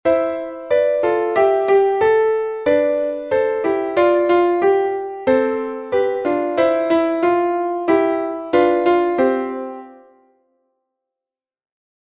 gif bongoHere's a canon to sing.
4. Dolce is an expression marking that means sweet and gentle.
Canon exercise 1
audio_canon_complete.mp3